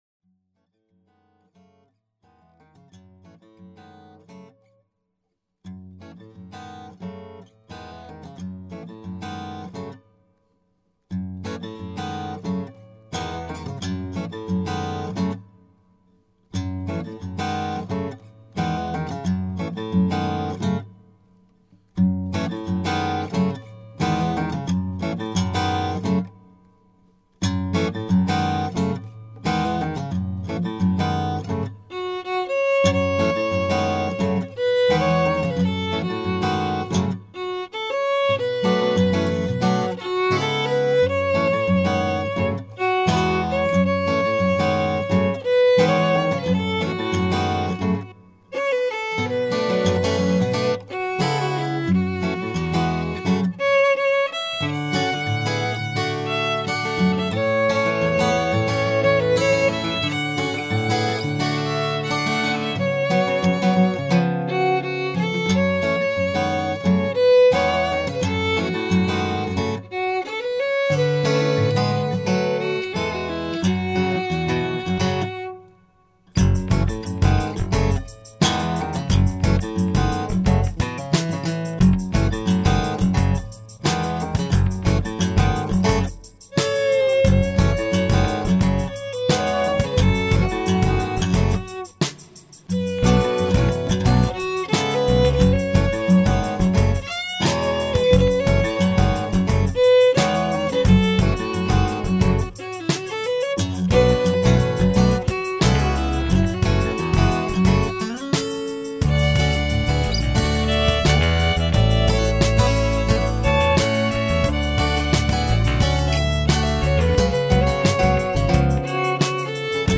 Fiddle
Guitar
Bass Traditional Arrangement copyright 2005